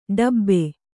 ♪ ḍabbe